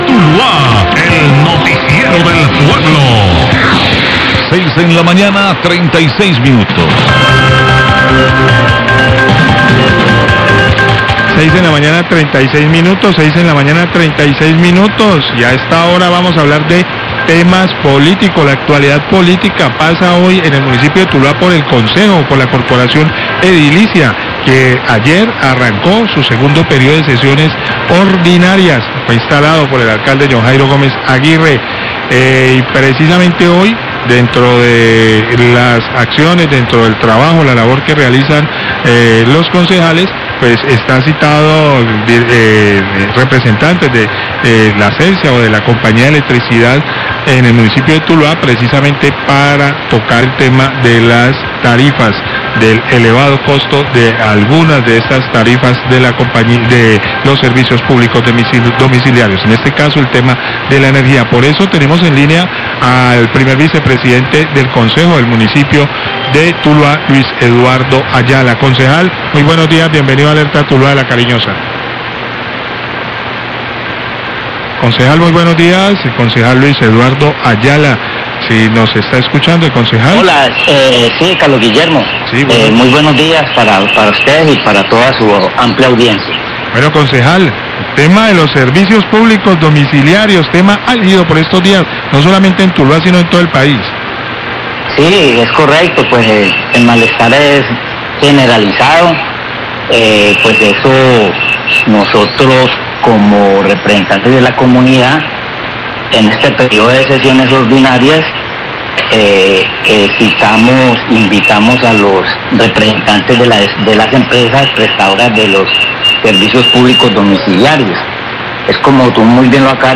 Radio
Concejal Luis Ayala habla de la citación que la corporación edilicia le hizo a la celsia en la jornada de sesiones ordinarias para que explique a la comunidad las razones por las cuáles se presentan tantas quejas por el incremento en el costo de los recibos y su relación el cobro por promedio.